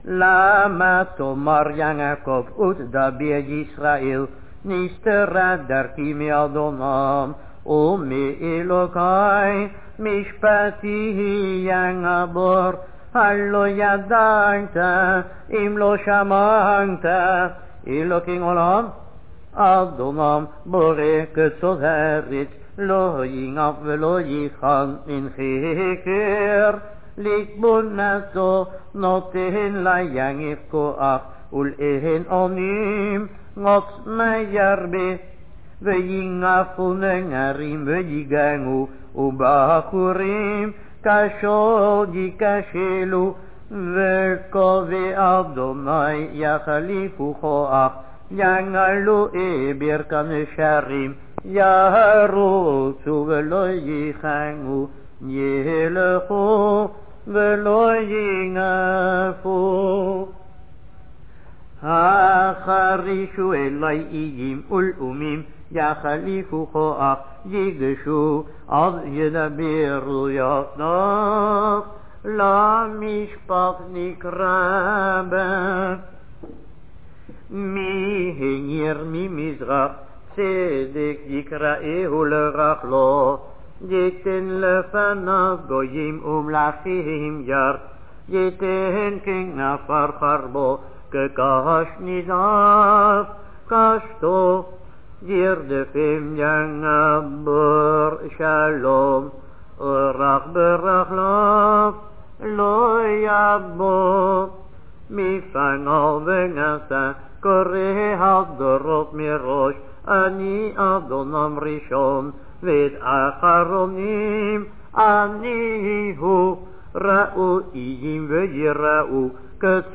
This structure is used during a private Limud (Lezing) and on 15 Shevat.
Some examples of the special Nevi'im melody: Melachim 1-6 11
Nevi段m during Limud are read with a special melody: example, list of neginot with recording.